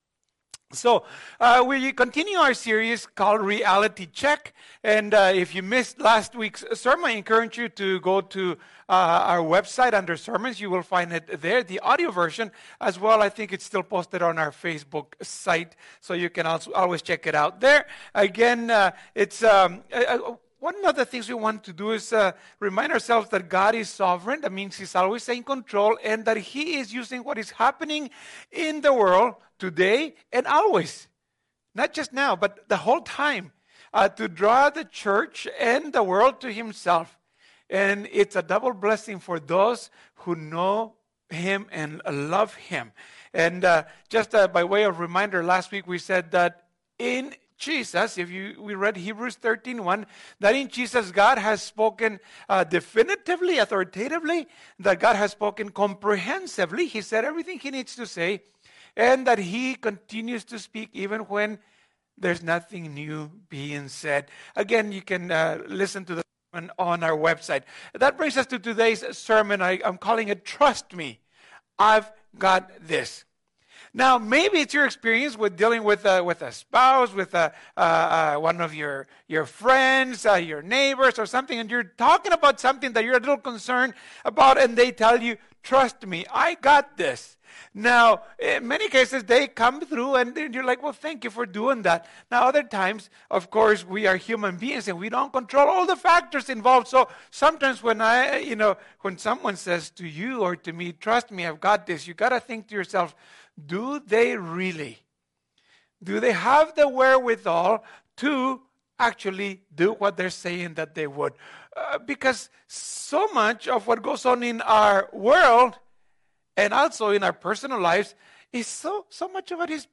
This is the second sermon in the Reality Check series.